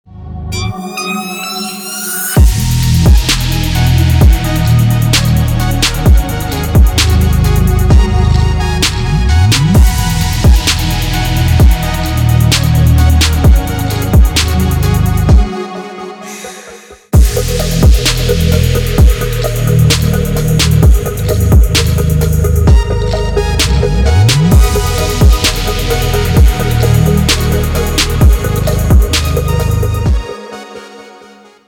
• Качество: 192, Stereo
Electronic
без слов
Trap
Bass
Стиль: Witch house